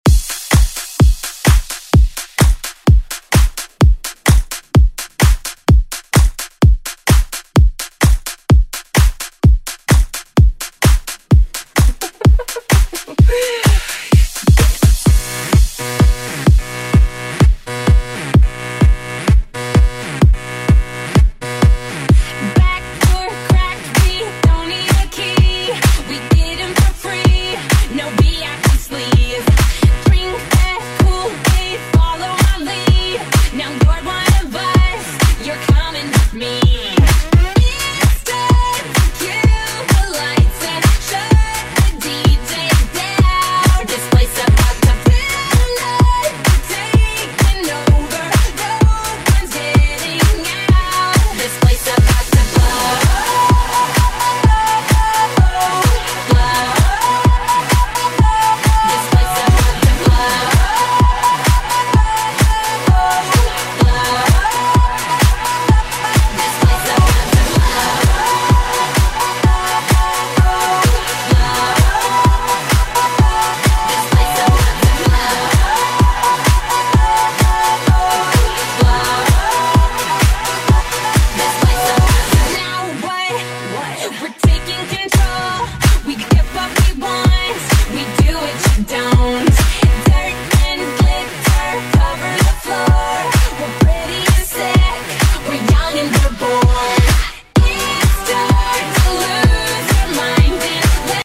Genre: 2000's
Clean BPM: 98 Time